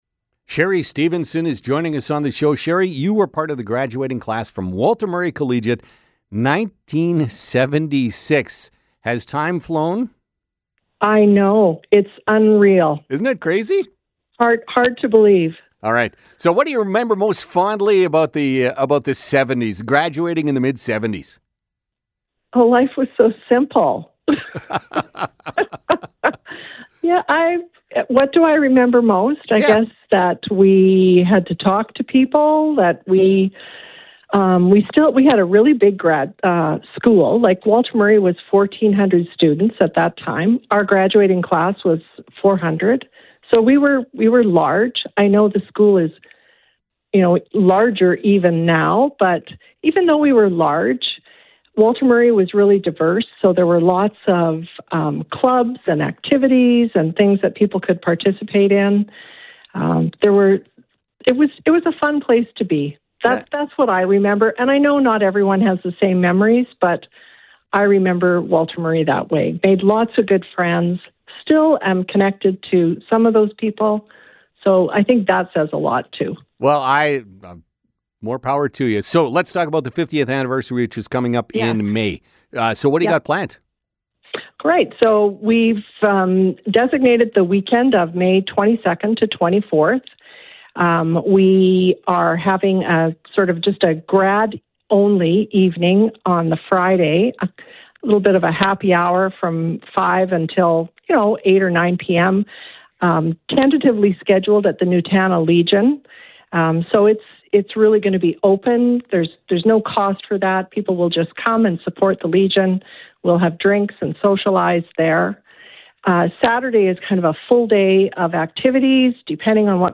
joined us in the Culligan Saskatoon Studio